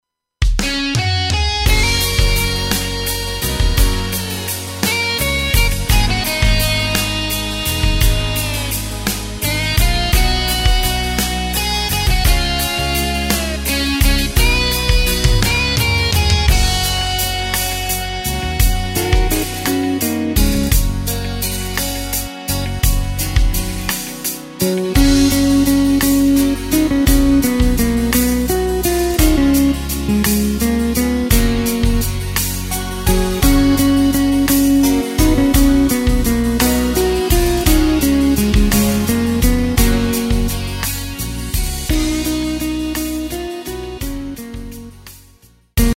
Takt:          6/8
Tempo:         85.00
Tonart:            F#
Schlager aus dem Jahr 2022!